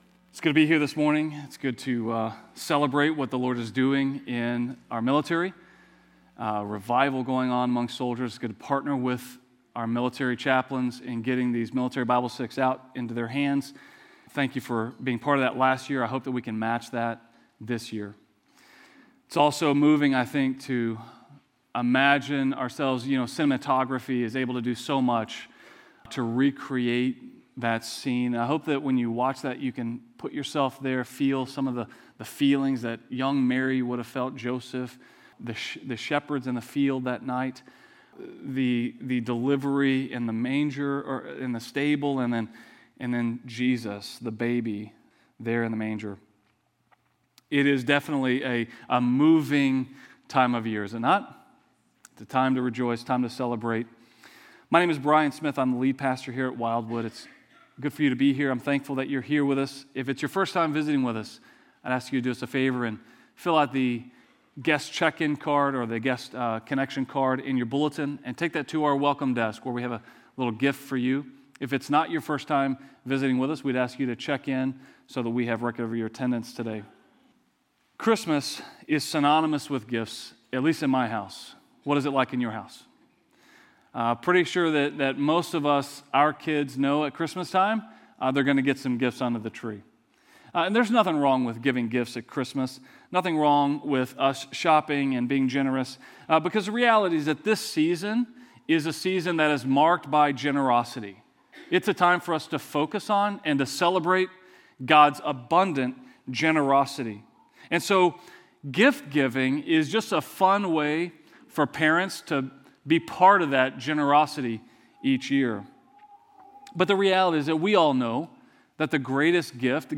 A message from the series "Advent 2020."